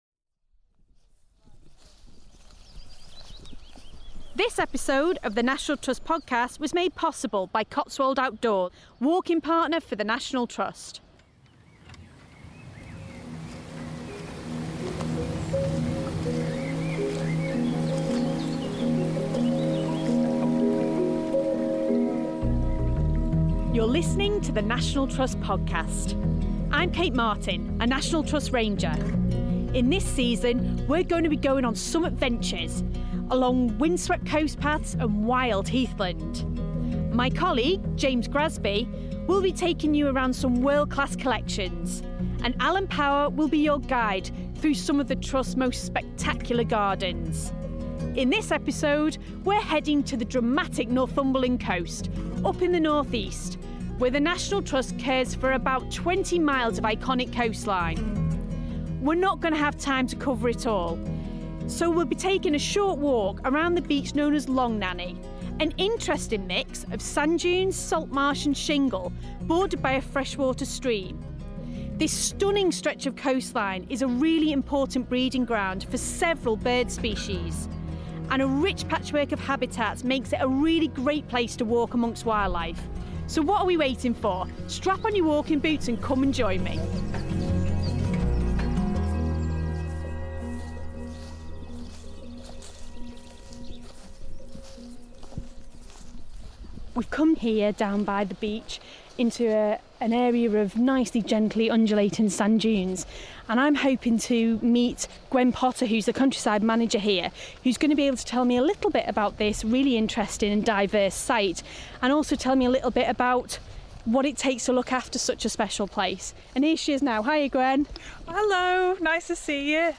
Learn to identify the birds that breed here each year, including ringed plovers and rare little terns and hear from the dedicated staff who camp out under the stars to protect them.